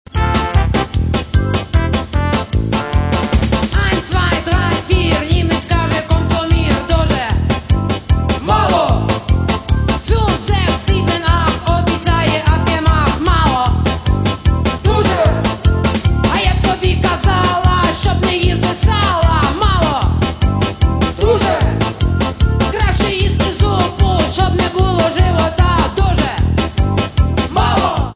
Каталог -> Поп (Легкая) -> Юмор
Легкая и энергичная музыка, шутка в каждой строке.